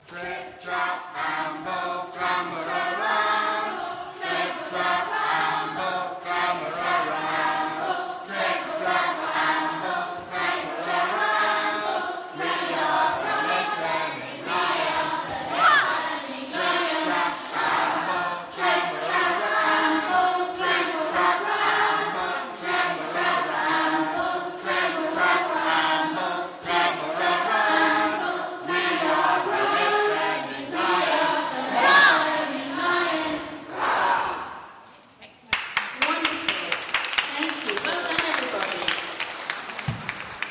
Singing History Concert 2016: Fitz Trip Trap 2